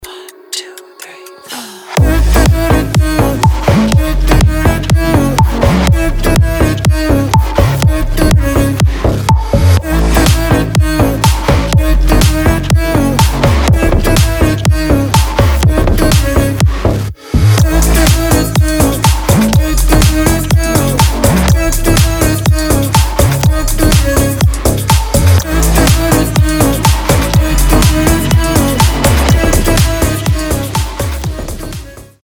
• Качество: 320, Stereo
EDM
мощные басы
future house
чувственные
качающие
тиканье часов
slap house